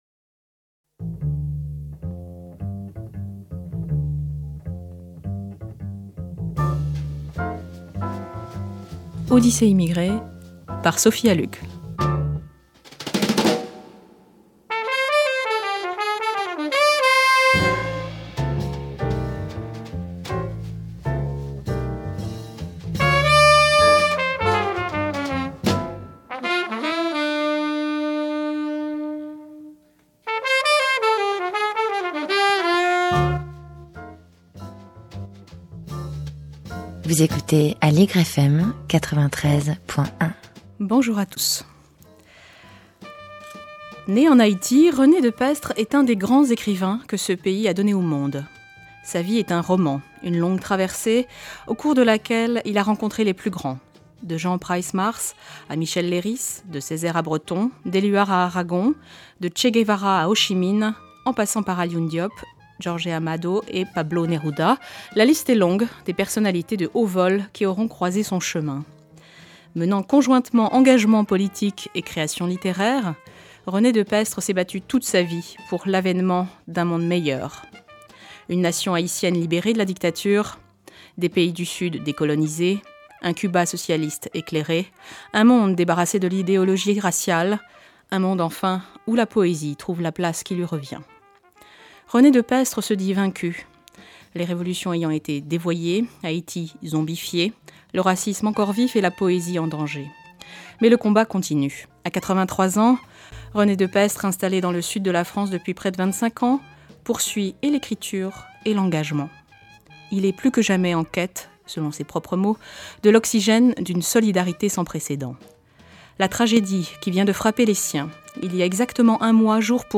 Enregistrée un mois après le tremblement de terre d’Haïti de 2010, cette émission est donc une rediffusion.